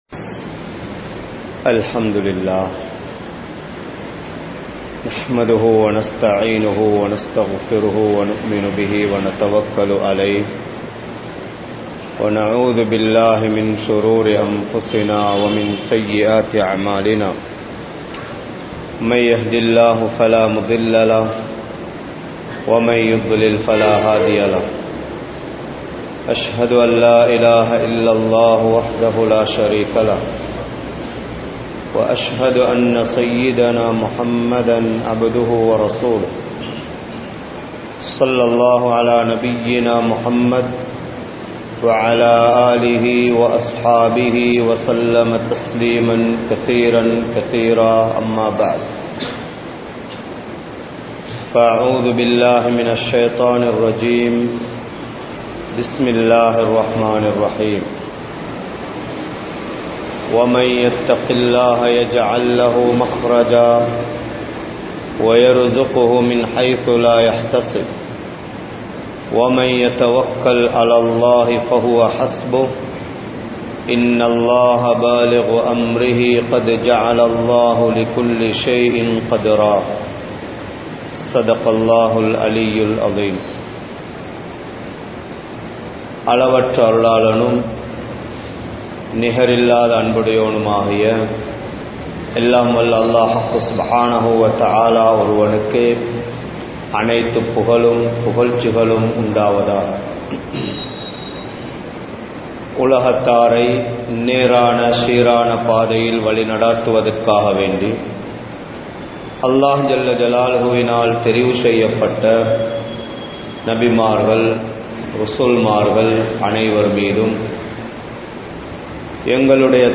Neengal Muslima? Muhmina? (நீங்கள் முஸ்லிமா? முஃமினா?) | Audio Bayans | All Ceylon Muslim Youth Community | Addalaichenai
Kurunegala, Mallawapitiya Jumua Masjidh